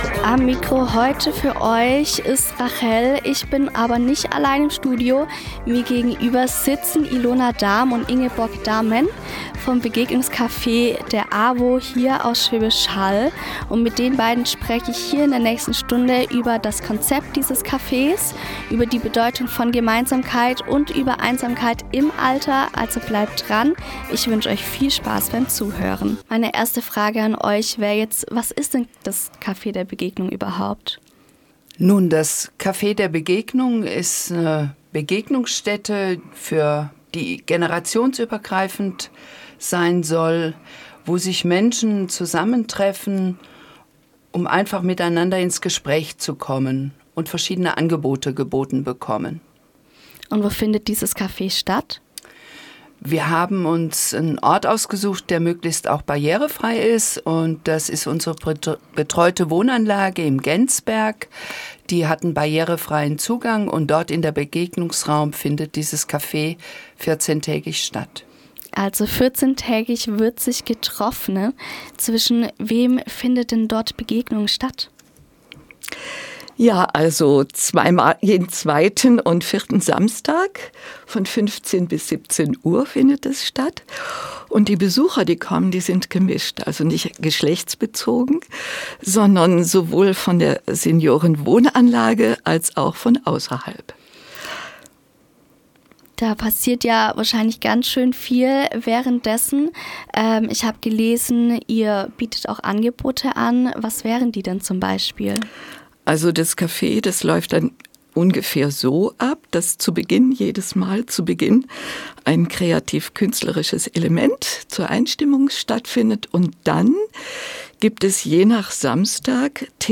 begegnungscafe-interview-homepage.mp3